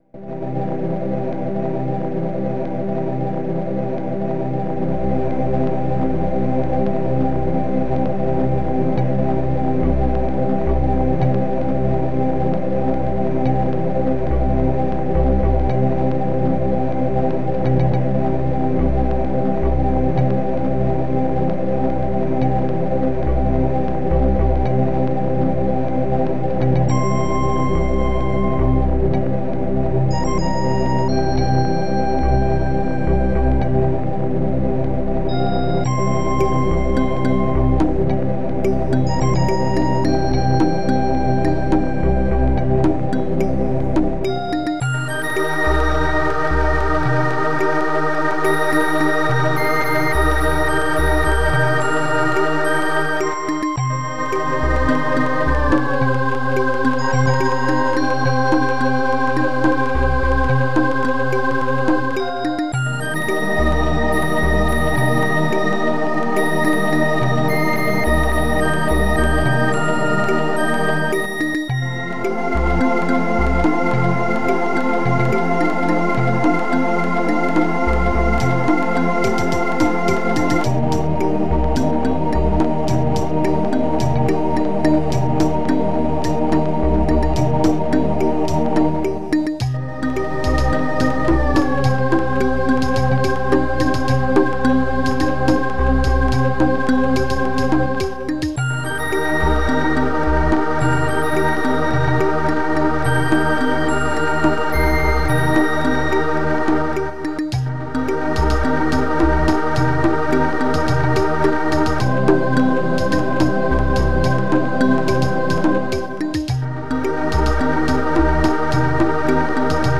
Protracker Module
st-17:xylophone st-17:cptn-ding st-17:s220-chorus st-00: st-61:bass10 st-65:perc-handdrum st-17:s220-chorus.maj st-64:snare16